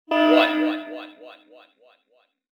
selfdestructone.wav